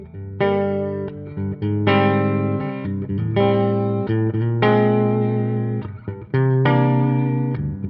Par contre à l'enregistrement le diezel devient plus crémeux, plus chaud et l'xtc plus baveux, quant à la dynamique elle n'est absolument pas restituée...
Bon vite fait avant d'aller bosser voici 4 samples Diezel et 1 Bogner.
C'est pas terrible: y a trop d'effet, c'est pas accordé, y a du plantage dans tous les sens, et les niveaux d'enregistrement sont trop forts ce qui provoque des pok (surtout sur les 2 derniers samples )...
Diezel sample.mp3